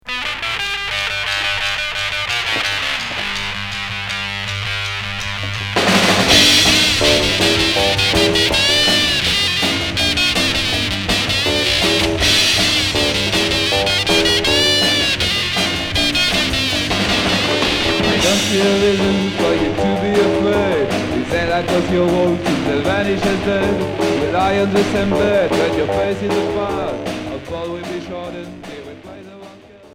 Psyché punk low-fi